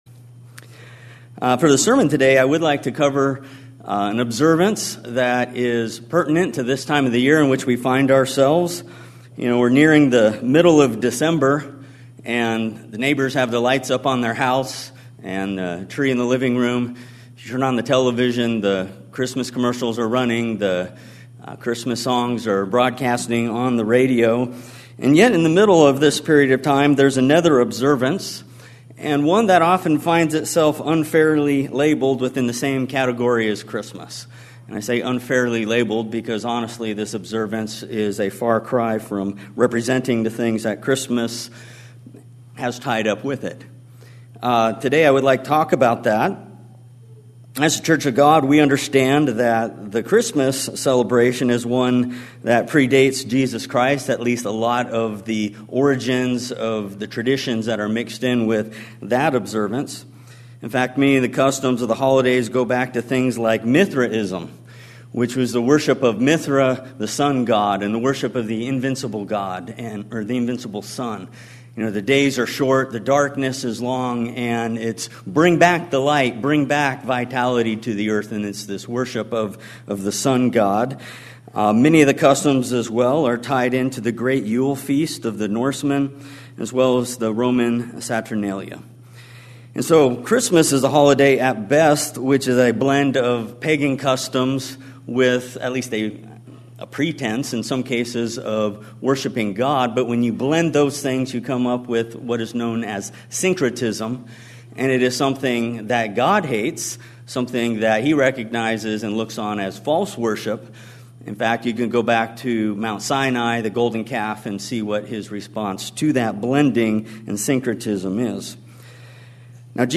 Note: This sermon contains readings from the First Book of Maccabees chapters 1-4 out of The World English Bible (WEB), a modern English translation in the public domain.